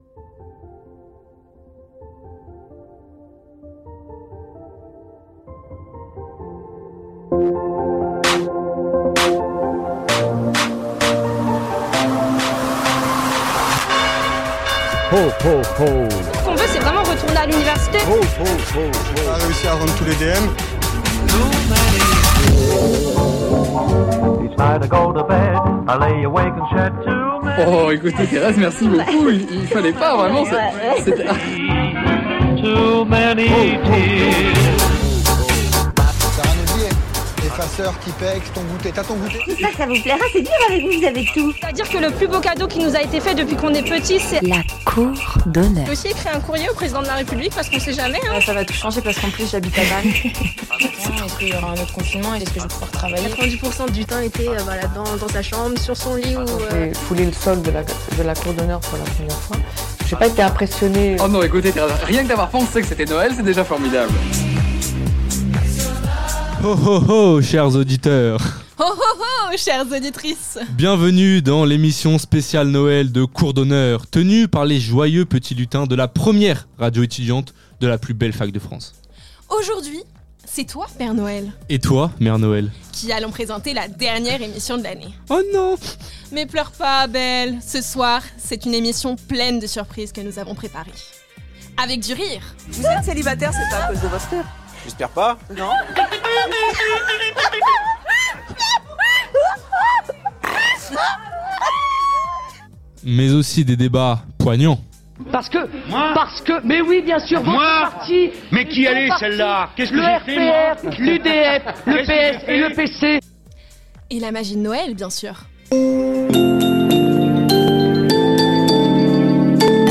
La vie étudiante à la Sorbonne sous toutes ses facettes. Anecdotes sur la plus vieille fac de France, interview d'enseignants, reportages sur le quotidien des étudiants, carte postale sonore des lieux emblématique de l'université.